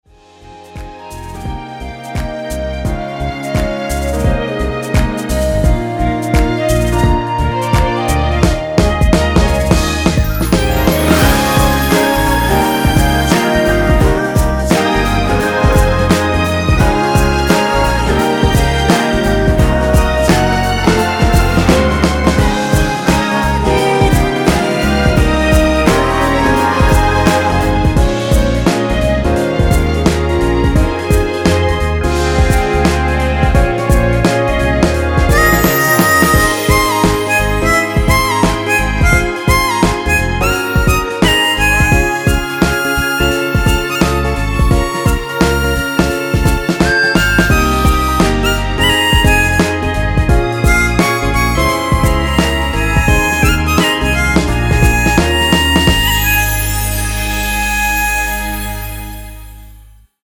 엔딩이 페이드 아웃이라서 노래하기 편하게 엔딩을 만들어 놓았으니 미리듣기 확인하여주세요!
원키 멜로디와 코러스 포함된 MR입니다.
Ab
앞부분30초, 뒷부분30초씩 편집해서 올려 드리고 있습니다.